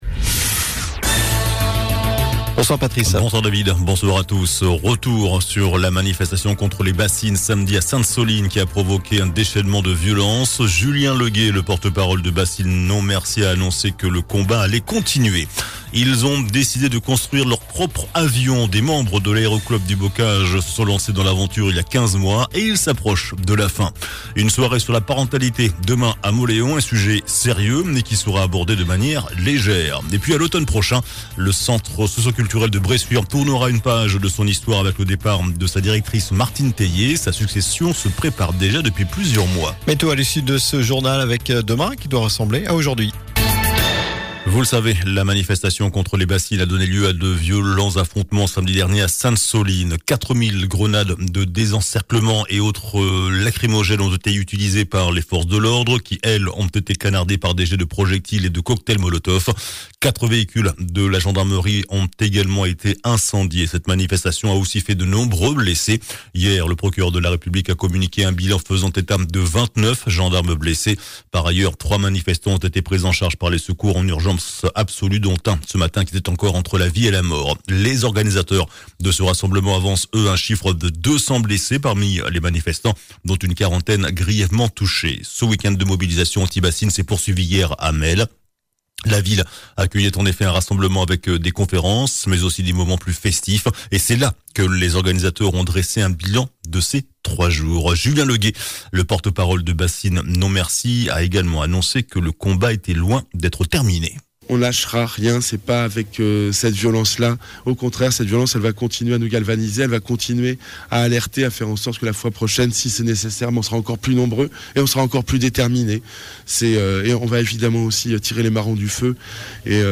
JOURNAL DU LUNDI 27 MARS ( SOIR )